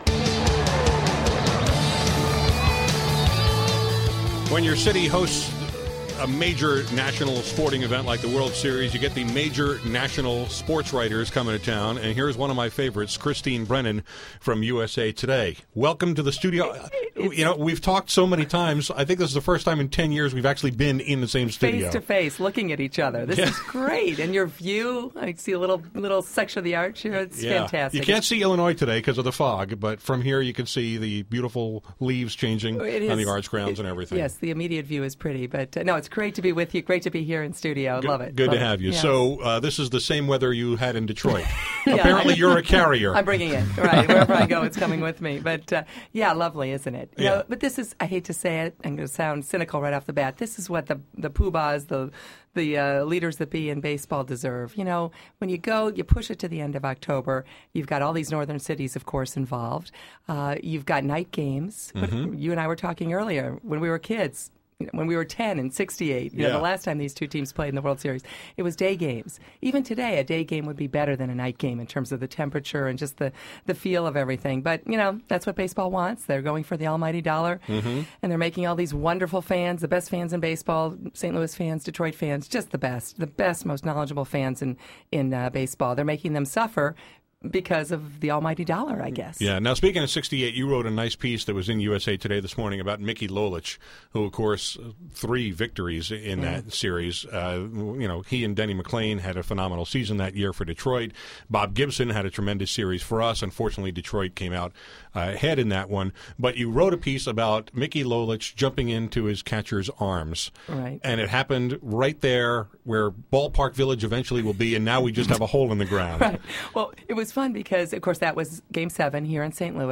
Christine Brennan stopped into my studio today while she’s here writing about the World Series for USA Today. We talked about the Kenny Rogers controversy, whether the new baseball labor agreement will do anything about steroids, and more.